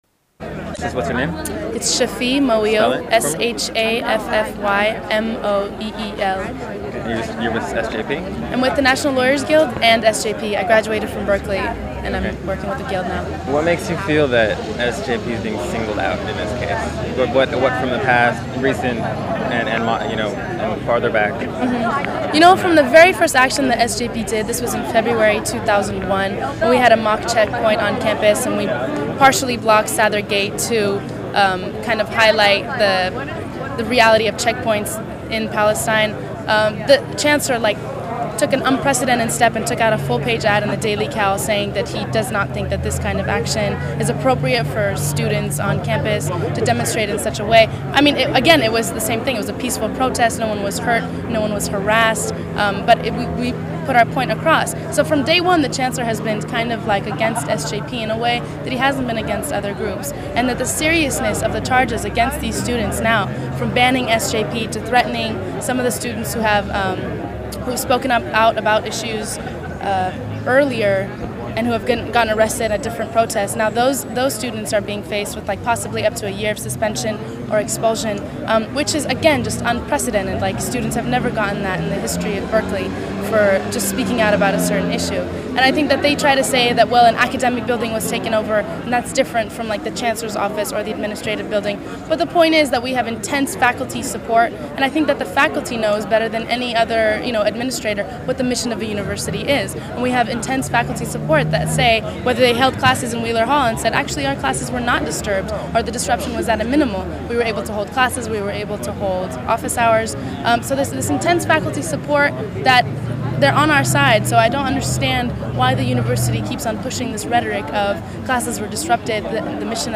Statements from NLG and SJP from a 4/30 press conference held in front of the Berkeley courthouse
Interview